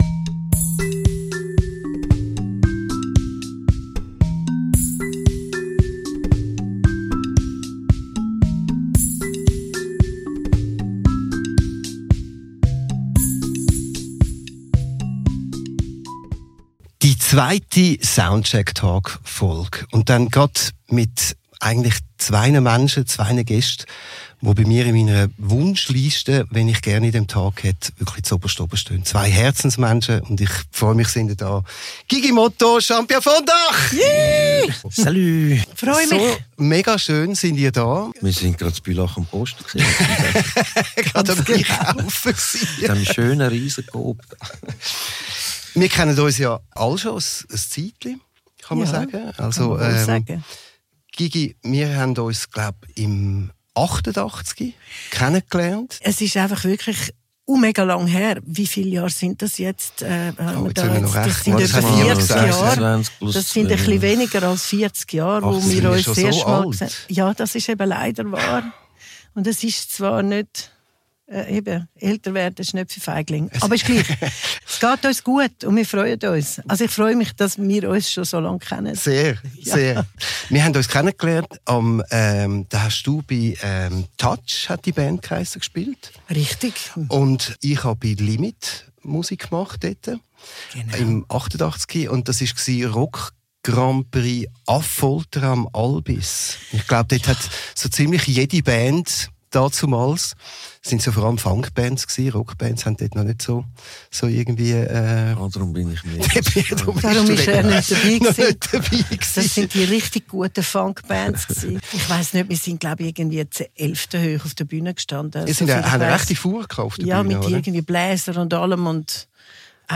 Live aus dem Soundcheck Recording Studio.